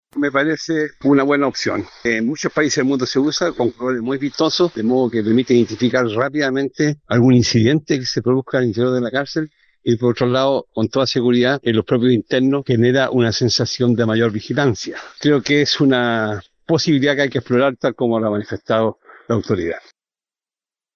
El exdirector de Gendarmería, Claudio Martínez, en tanto, aseguró que una medida de estas características permitirá una mayor vigilancia, y también, más seguridad para los funcionarios, e incluso, los propios reos.